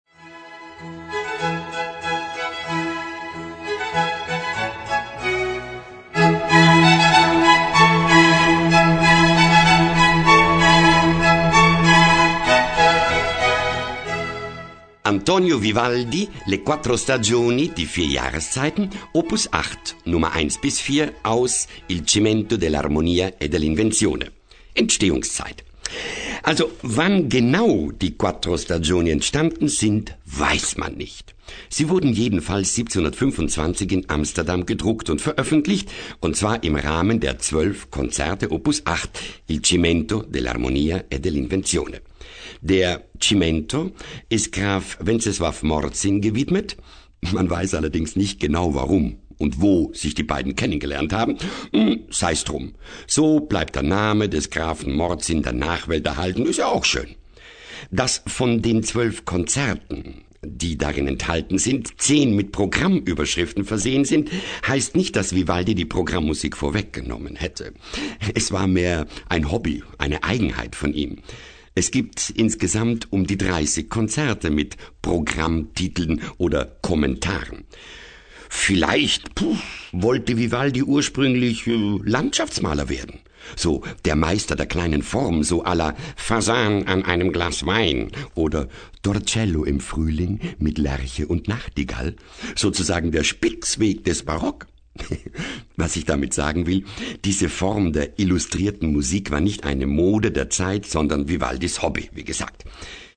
Konrad Beikircher (Sprecher)
Lesung mit Einspielungen internationaler Orchester.